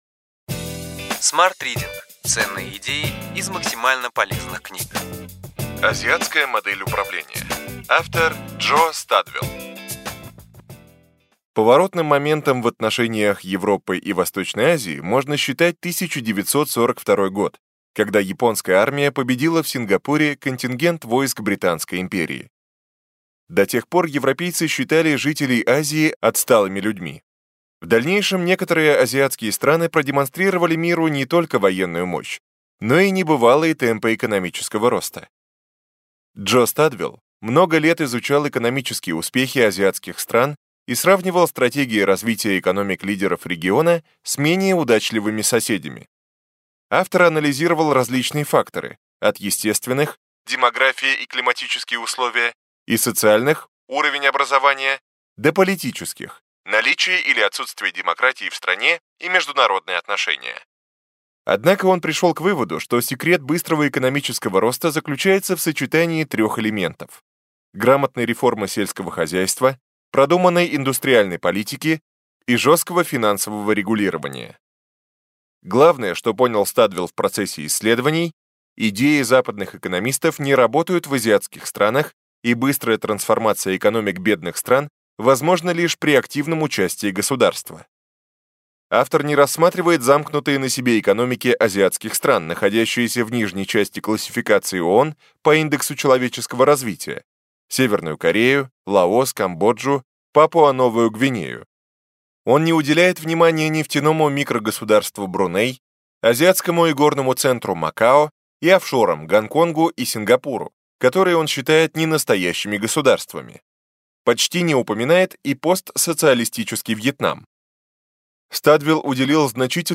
Аудиокнига Ключевые идеи книги: Азиатская модель управления.